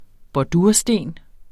Udtale [ bɒˈduɐ̯ˀ- ]